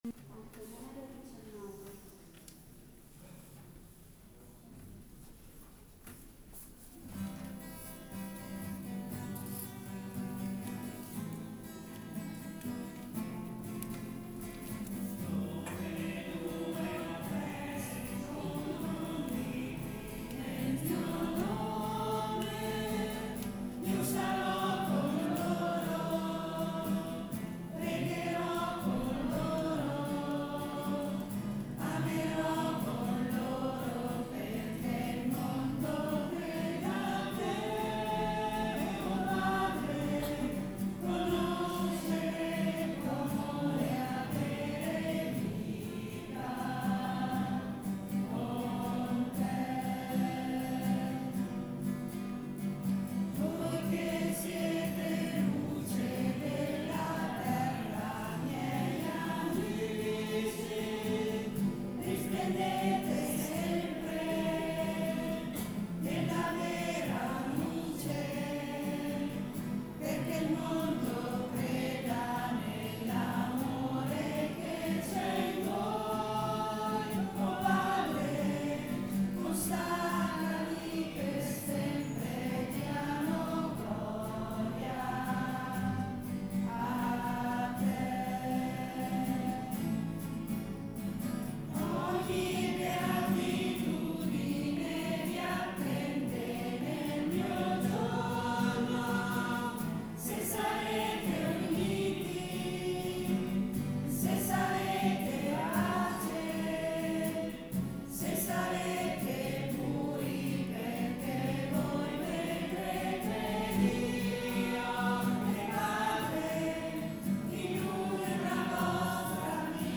Coro Parrocchiale
Il coro parrocchiale di Bastiglia si incontra due lunedì al mese dalle ore 20.30 presso l’oratorio oppure in sagrestia.